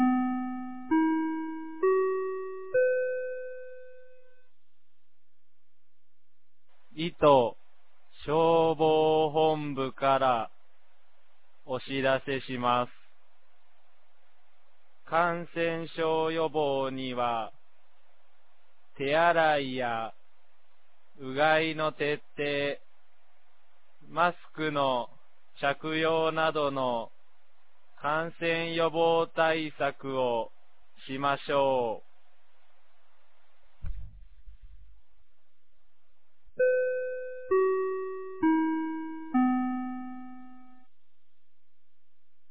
2025年12月18日 10時46分に、九度山町より全地区へ放送がありました。